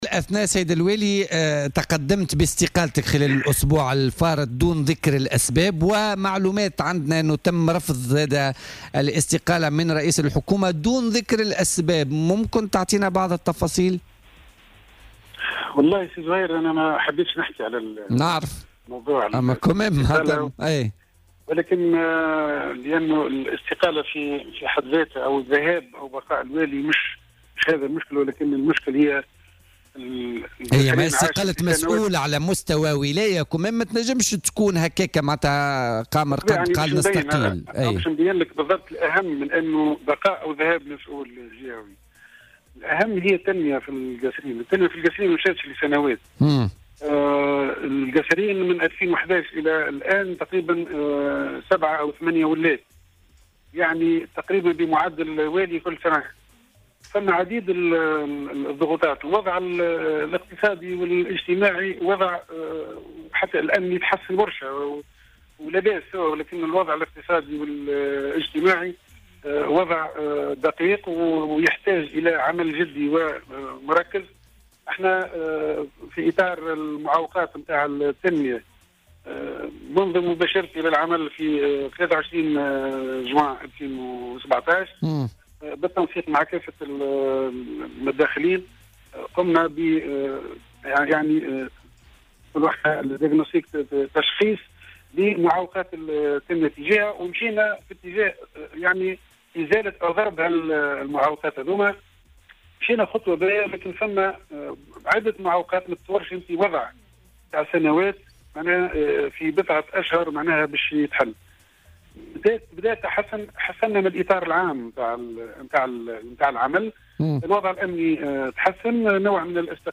وقال في مداخلة هاتفية مع "بوليتيكا" إن بعض الأطراف لا يخدمها استقرار المنطقة بعد الانطلاق في عملية تفكيك منظومة الفساد وما يعنيه ذلك من ضرب لمصالحها، بحسب تعبيره متحفظا عن الكشف عن هوية هذه الأطراف وعن نشاطها بالمنطقة وعما إذا كانت هي السبب المباشر لتقديم طلب الاستقالة.